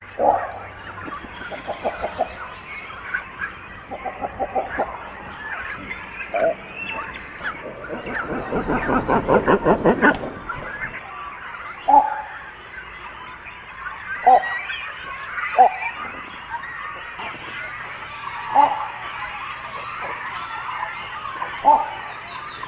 65. MERIMETSO (storskarv)
Äänet: Yleensä hiljainen. Pesällä korisevia kurkkuääniä.
merimetso-copyright-birdlife.mp3